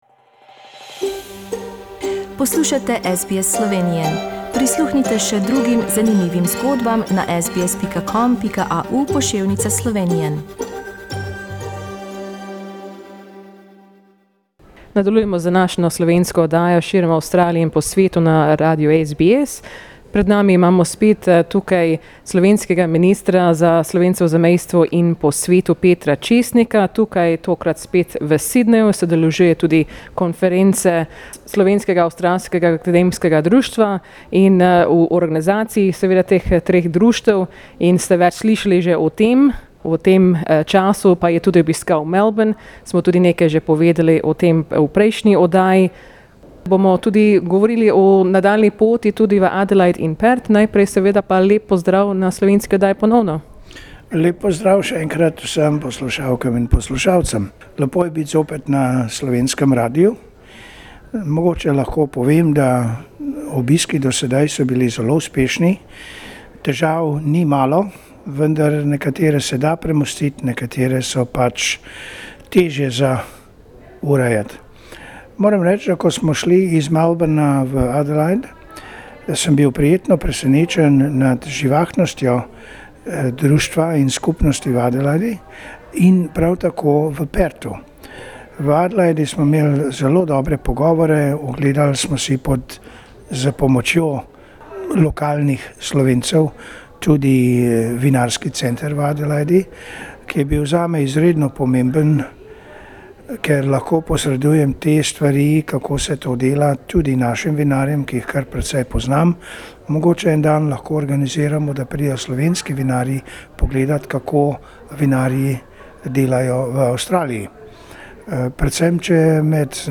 Slovenski Minister za Slovence v zamejstvu in po svetu, Peter Česnik, je te dni zaključil obisk v Avstraliji. Pogovarjali smo se na 3. letni konferenci Slovenskega Avstralskega Akademskega društva v Sydneyu, kjer je bil govornik.